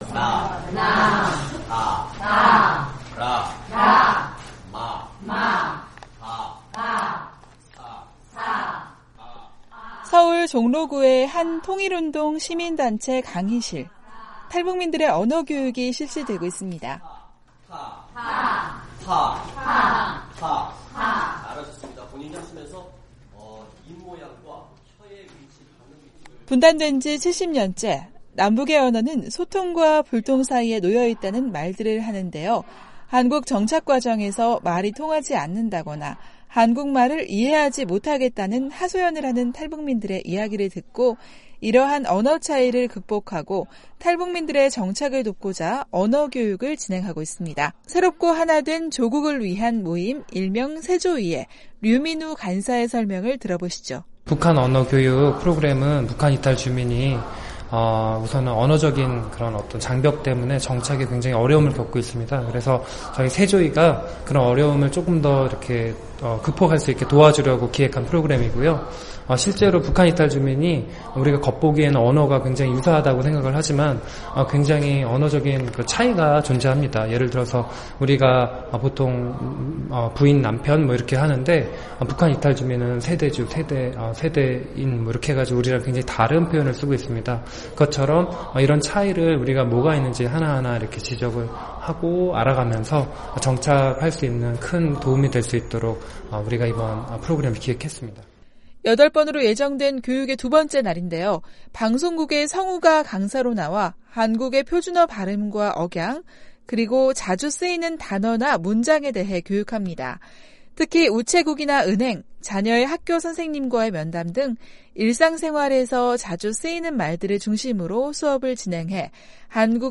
지난 7일 서울 종로구의 통일운동시민단체 '새롭고 하나된 조국을 위한 모임' 강의실에서 탈북민을 위한 언어교육이 진행 중이다.